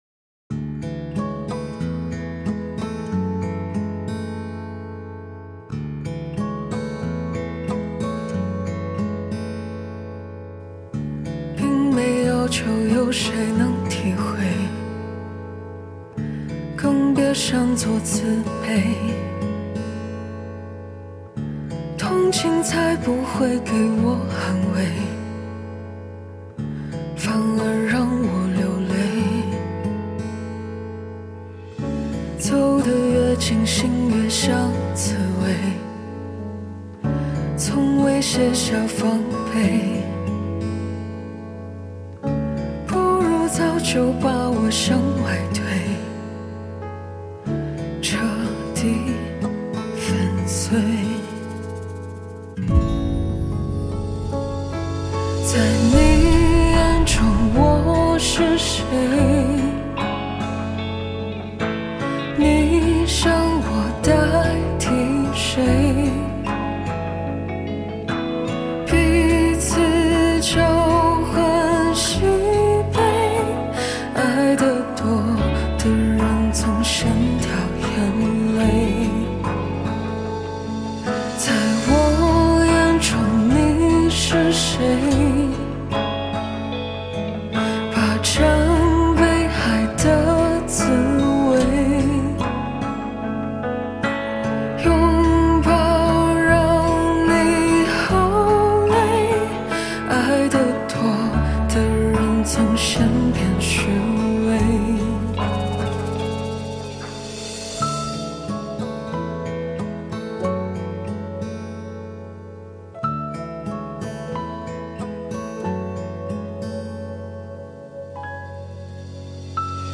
Ps：在线试听为压缩音质节选，体验无损音质请下载完整版 混音